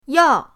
yao4.mp3